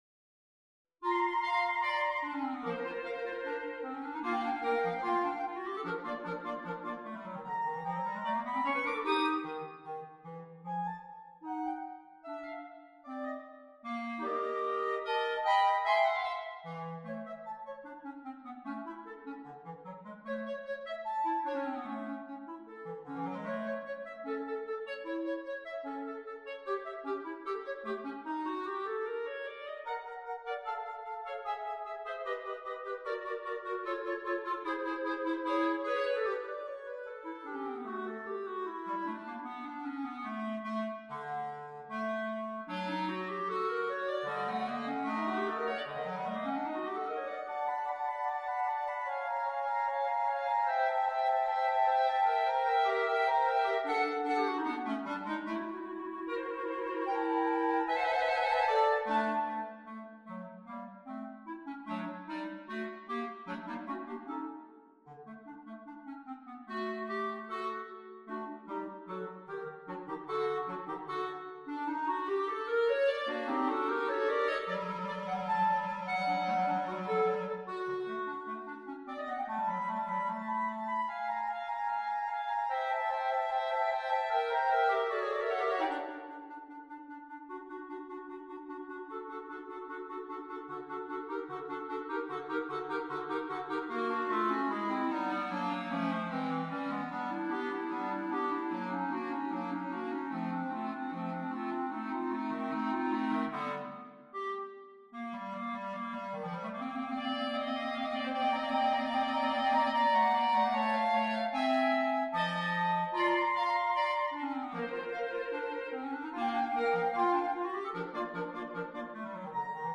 per tre clarinetti
per 3 clarinetti